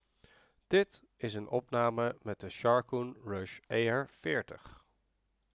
Hieronder een opname met de SureFire Gaming Harrier 360 en een opname met de Sharkoon Rush ER40 als vergelijking.
Opname met de Sharkoon Rush ER40